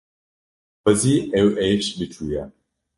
Pronúnciase como (IPA) /eːʃ/